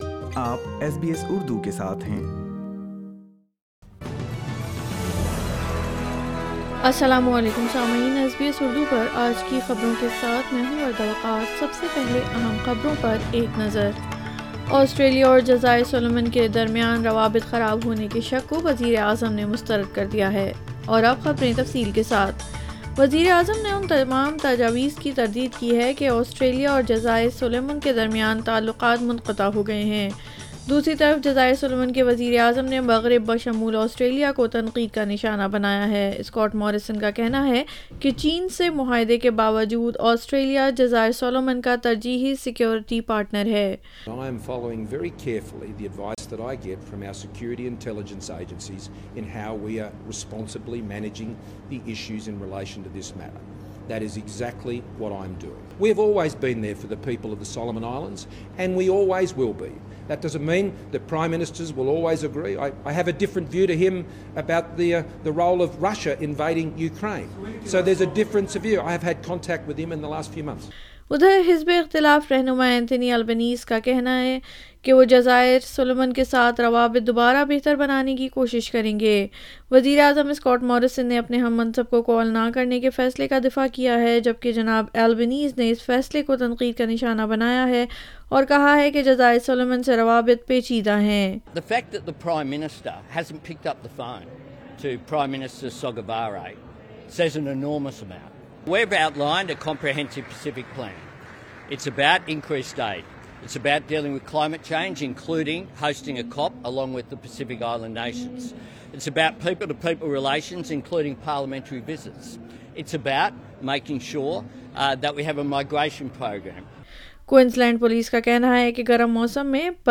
SBS Urdu News 05 May 2022